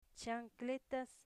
Phonological Representation ʧan'kletas